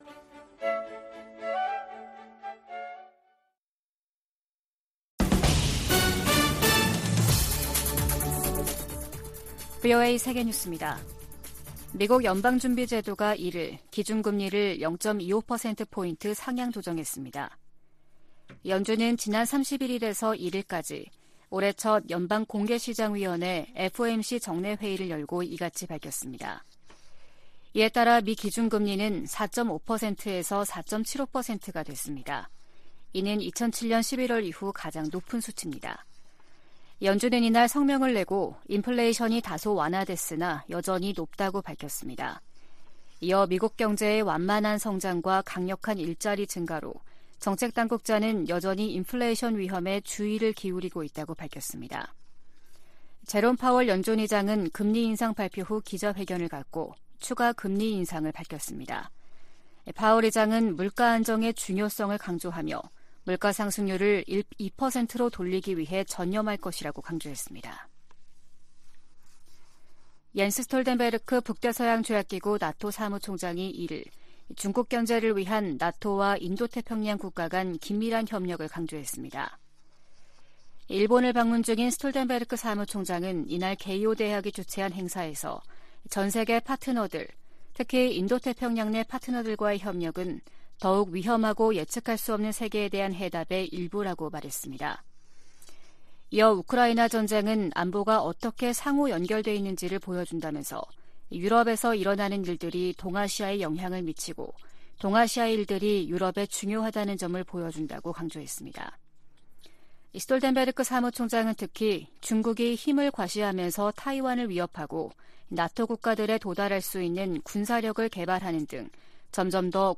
VOA 한국어 아침 뉴스 프로그램 '워싱턴 뉴스 광장' 2023년 2월 2일 방송입니다. 오는 3일 워싱턴에서 열릴 미-한 외교장관 회담에서는 북한의 도발 행위를 억제하는 중국의 역할을 끌어내기 위한 공조 외교를 펼 것이라는 관측이 나오고 있습니다. 미국 국무부가 한국에서 독자 핵 개발 지지 여론이 확대되는 것과 관련해, 핵무장 의지가 없다는 윤석열 정부의 약속을 상기시켰습니다.